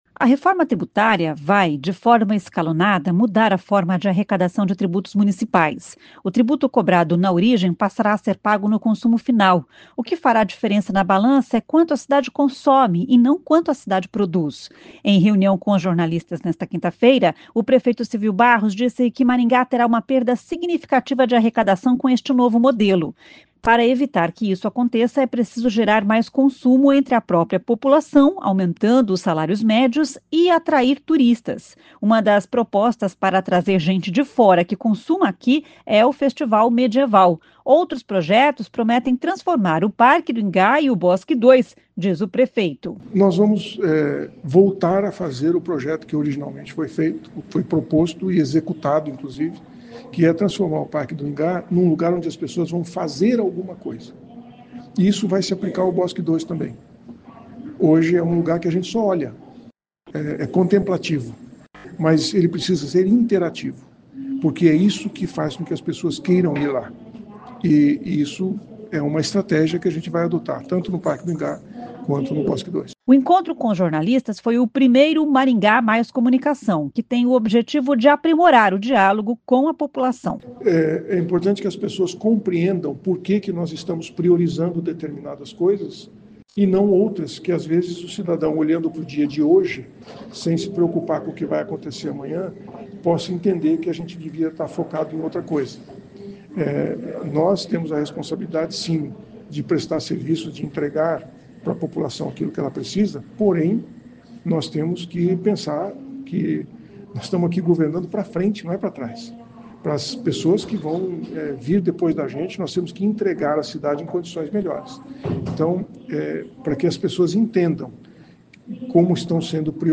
Em reunião com jornalistas nesta quinta-feira (3), o prefeito Silvio Barros disse que Maringá terá uma perda significativa de arrecadação com este novo modelo.